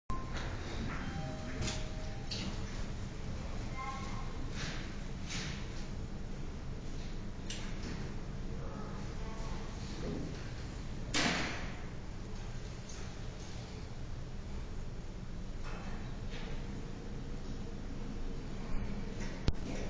※実際に豊洲のタワーマンションに納入したED-100の自動で開閉する様子を動画でご覧いただけます。 ED-100 開閉動画（mp3）